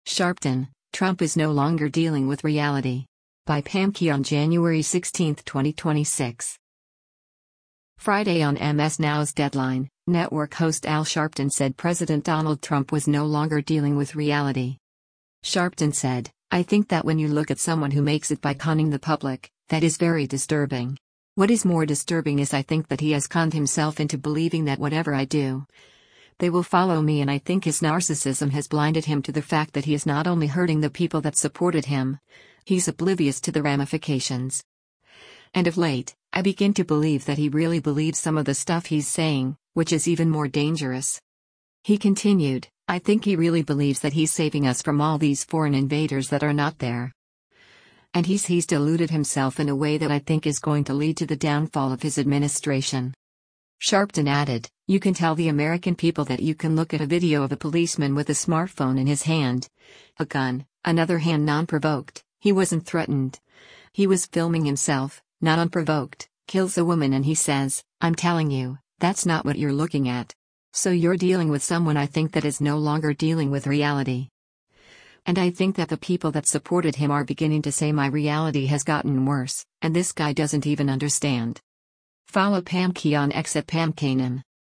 Friday on MS NOW’s “Deadline,” network host Al Sharpton said President Donald Trump was “no longer dealing with reality.”